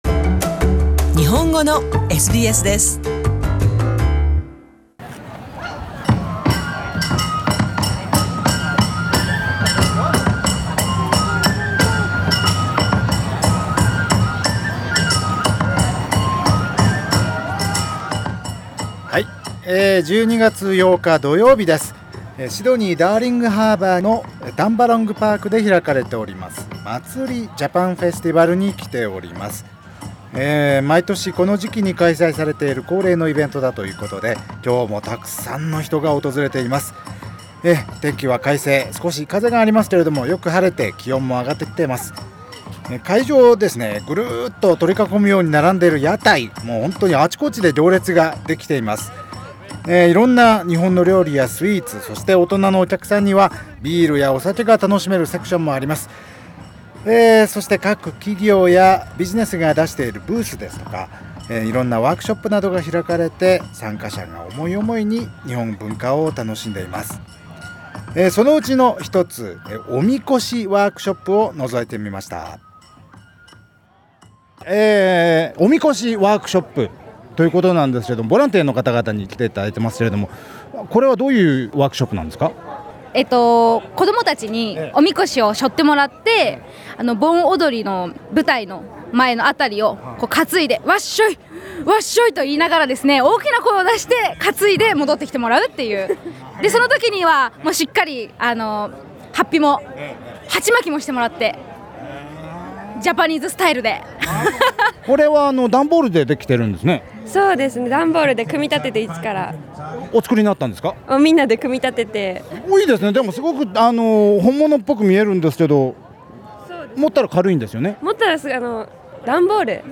12月8日土曜日、毎年恒例、シドニー日本人コミュニティーの夏のイベント、Matsuri Japan Festival が、ダーリングハーバーにあるタンバロング・パークで開かれました。今年もたくさんの参加者を集めたこのイベントから、現地リポートをお送りします。